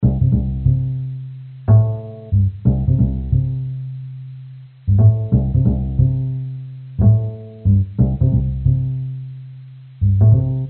南方之雨》假直板贝司
描述：4小节的假低音，用C调。
标签： 原声低音 低音 慢节奏 循环播放
声道立体声